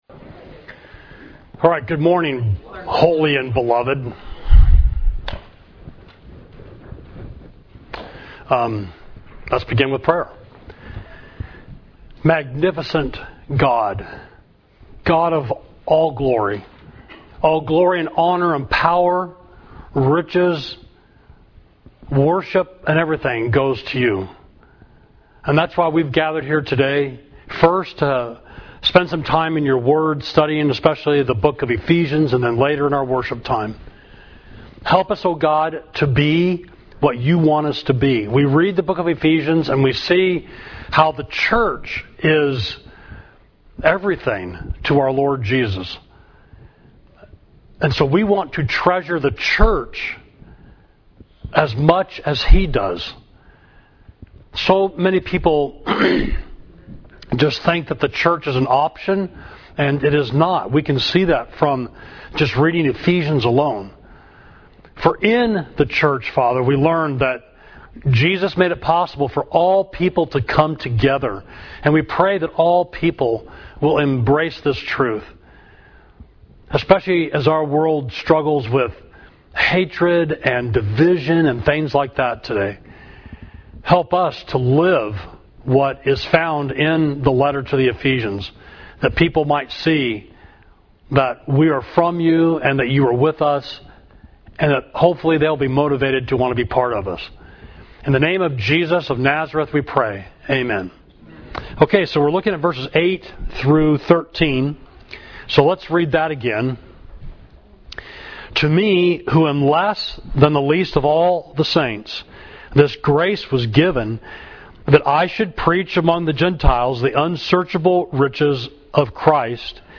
Class: The Church Makes Known God’s Wisdom, Ephesians 3.8–13